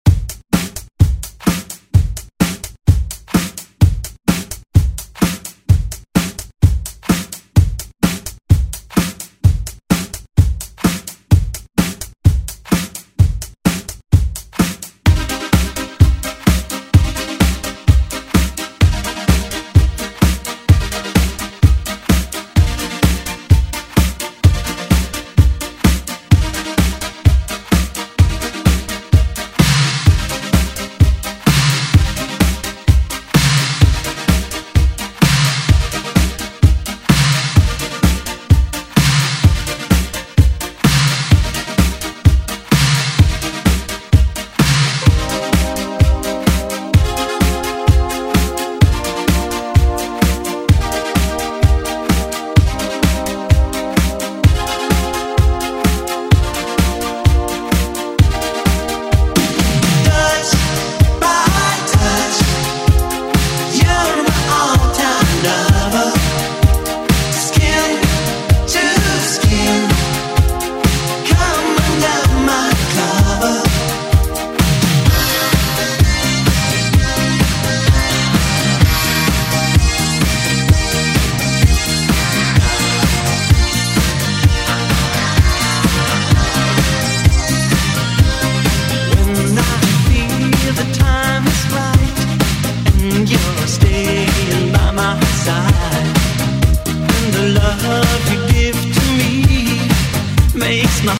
Genres: LATIN , MERENGUE , RE-DRUM
Clean BPM: 88 Time